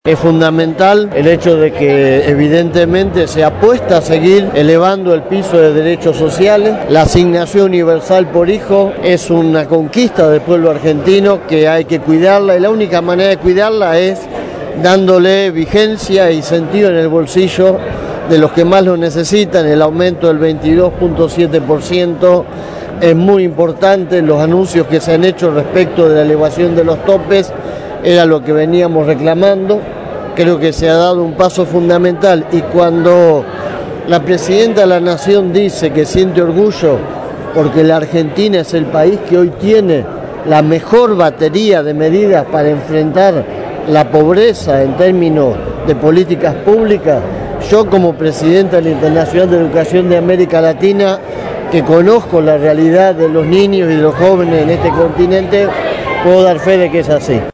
en el acto en donde se anunció el aumento del 22,7 por ciento para este beneficio.